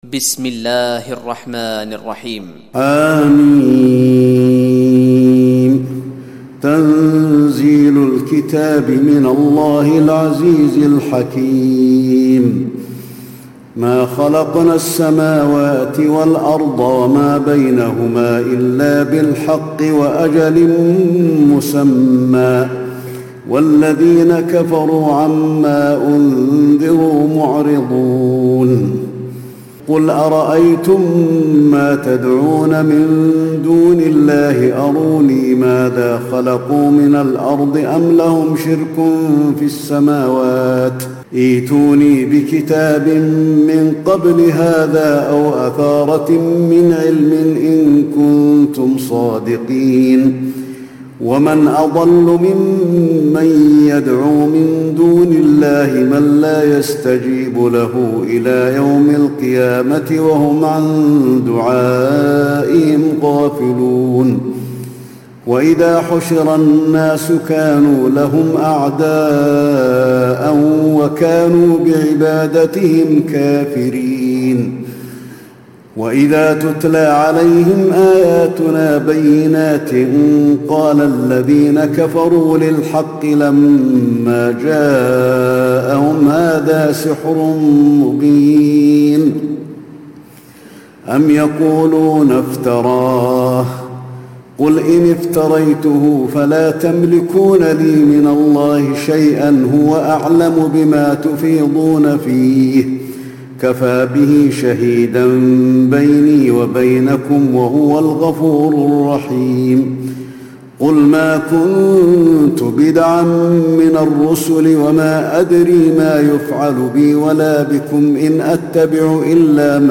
تراويح ليلة 25 رمضان 1436هـ سورتي الأحقاف و محمد Taraweeh 25 st night Ramadan 1436H from Surah Al-Ahqaf and Muhammad > تراويح الحرم النبوي عام 1436 🕌 > التراويح - تلاوات الحرمين